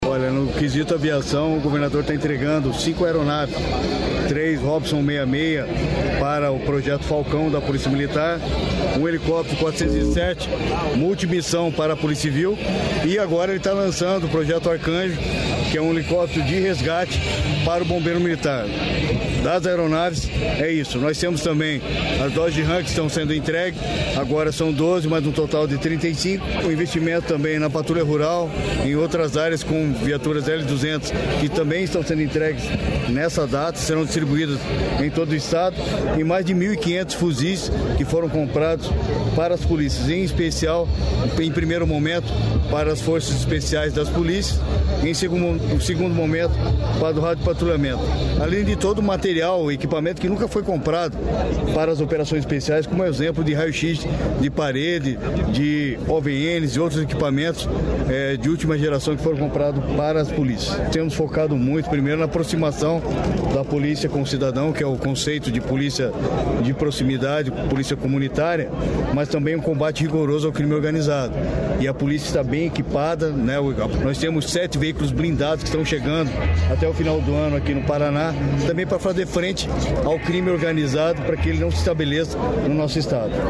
Sonora do secretário de Segurança Pública, Hudson Leôncio Teixeira, sobre os reforços na Segurança Pública